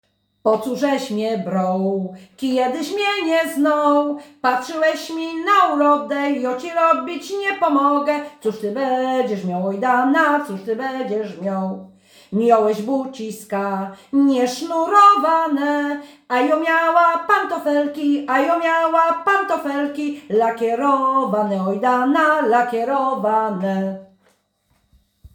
Po cóżeś mnie broł – Żeńska Kapela Ludowa Zagłębianki
Nagranie współczesne